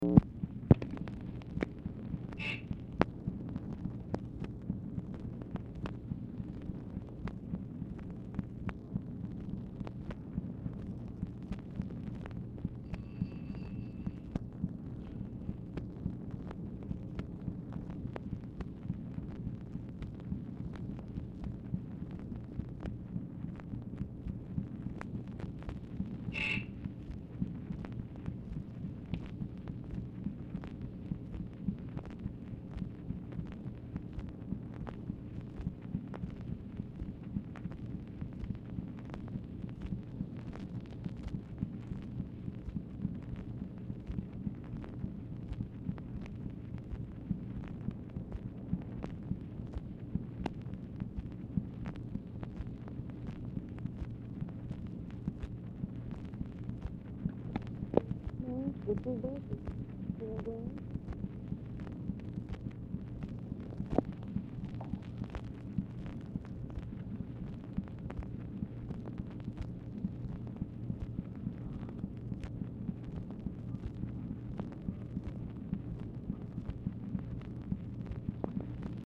Telephone conversation # 8649, sound recording, OFFICE NOISE, 8/26/1965, time unknown | Discover LBJ
Format Dictation belt
Location Of Speaker 1 Oval Office or unknown location